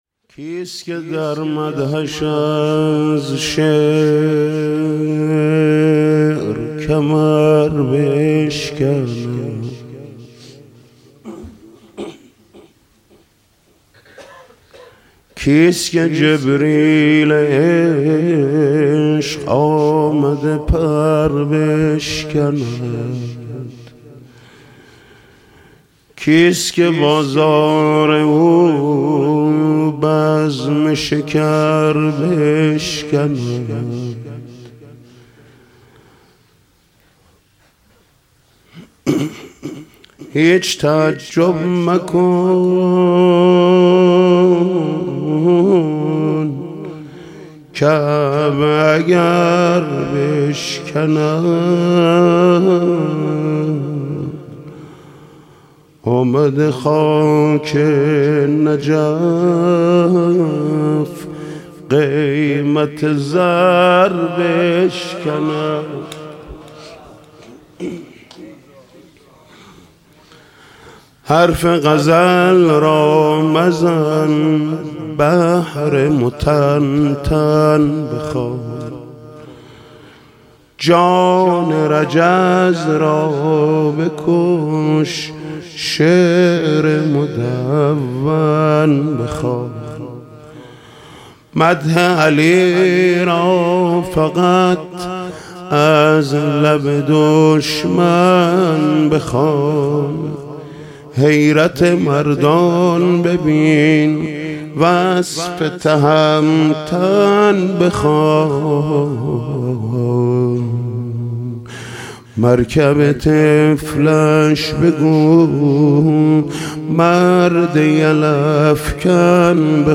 نوحه مخصوص عزاداری ایام فاطمیه با صدای محمود کریمی.